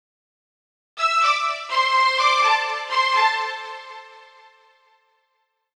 STRING001_VOCAL_125_A_SC3.wav